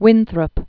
(wĭnthrəp), John 1588-1649.